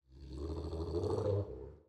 Babushka / audio / sfx / Animals / SFX_Wolf_Snarl_02.wav
SFX_Wolf_Snarl_02.wav